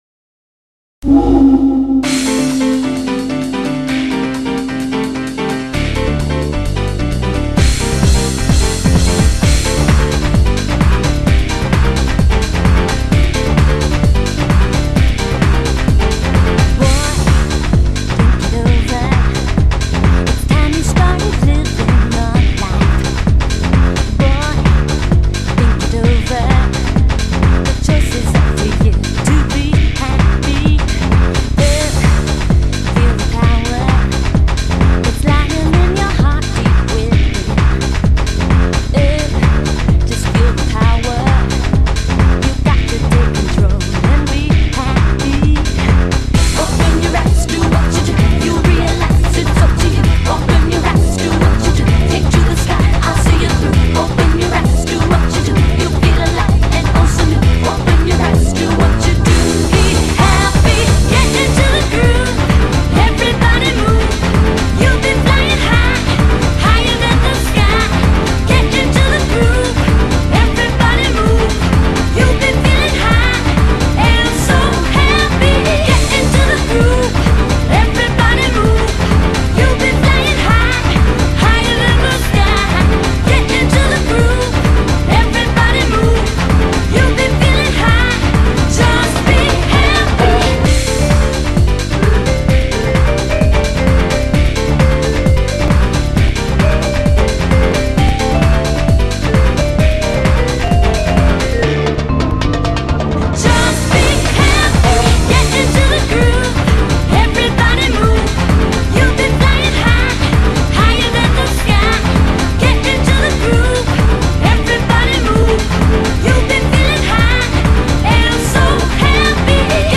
BPM130-130
Audio QualityCut From Video